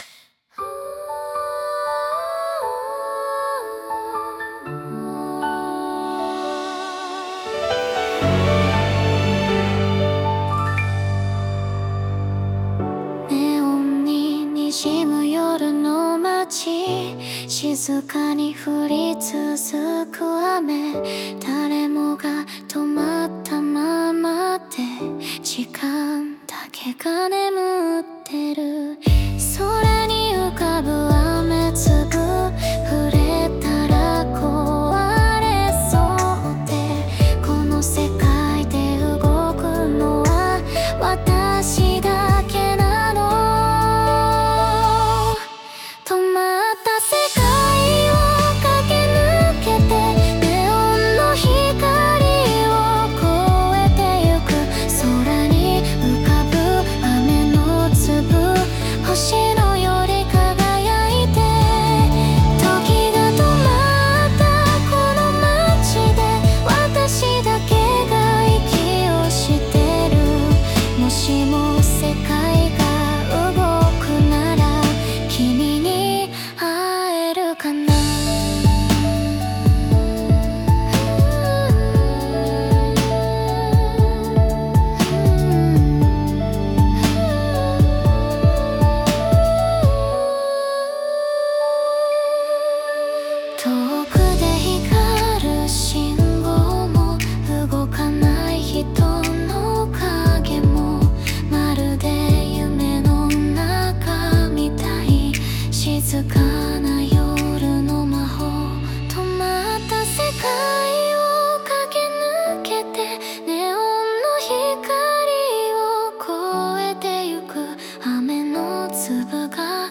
그래서 가사와 스타일을 추가하여 일본 애니메이션 오프닝 음악을 만들 수 있었습니다.
아름다운 일본 애니 스타일의 오프닝용 음악을 만들 수 있었습니다. 생각보다 아련한 음악이지 않나요?